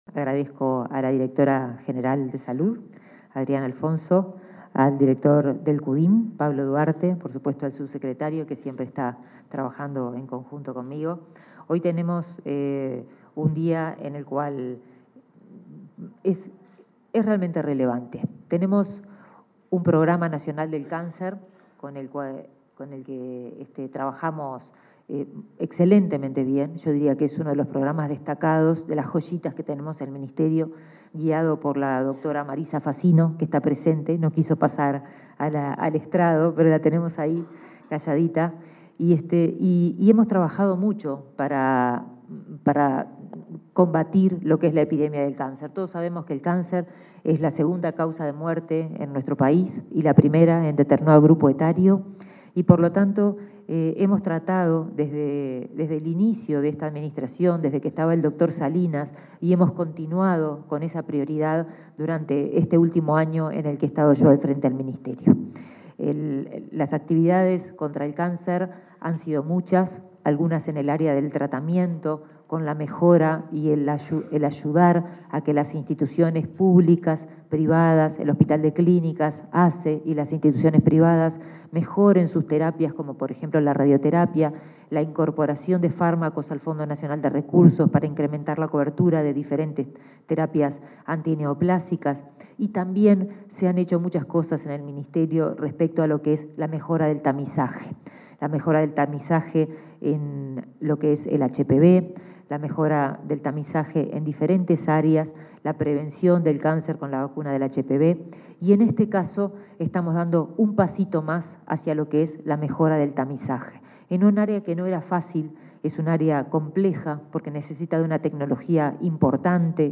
Palabras de la ministra de Salud Pública, Karina Rando | Presidencia Uruguay